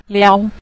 liao5.mp3